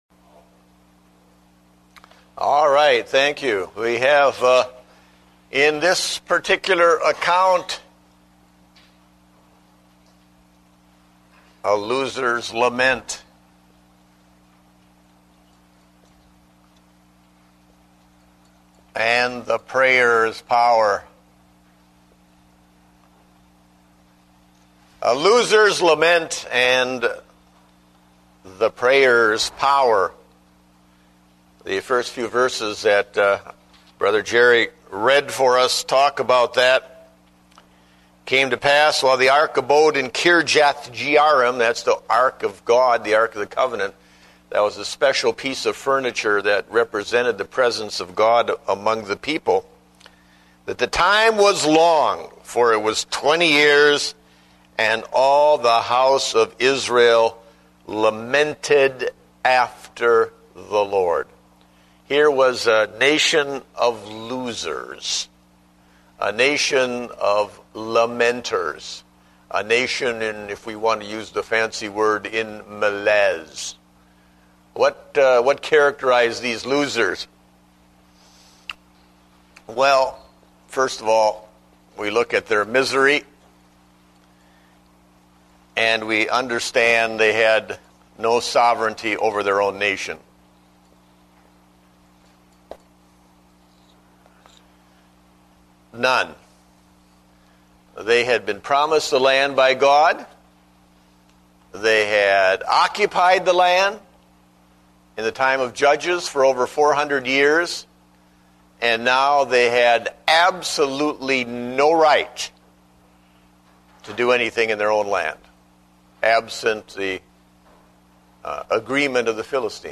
Date: September 20, 2009 (Adult Sunday School)